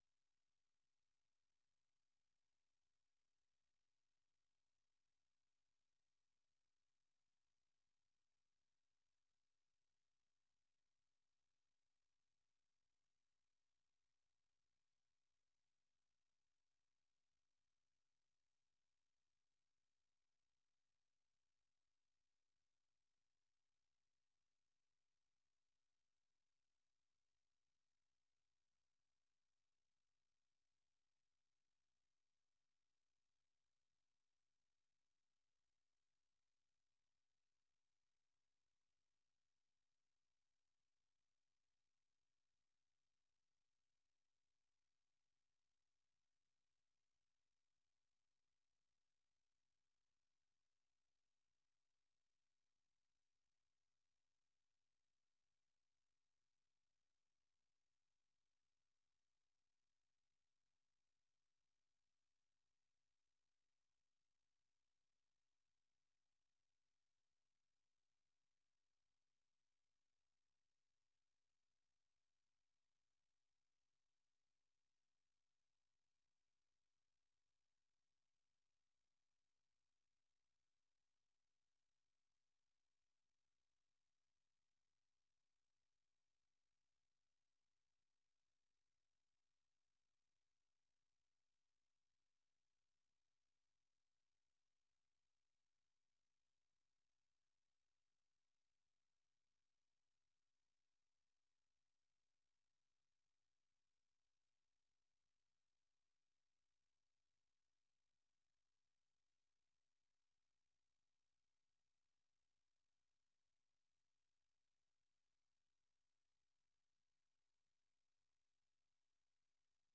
The URL has been copied to your clipboard 分享到臉書 分享到推特網 No media source currently available 0:00 0:30:00 0:00 下載 128 kbps | MP3 64 kbps | MP3 時事經緯 時事經緯 分享 時事經緯 分享到 美國之音《時事經緯》每日以30分鐘的時間報導中港台與世界各地的重要新聞，內容包括十分鐘簡短國際新聞，之後播出從來自世界各地的美國之音記者每日發來的採訪或分析報導，無論發生的大事與你的距離是遠還是近，都可以令你掌握與跟貼每日世界各地發生的大事！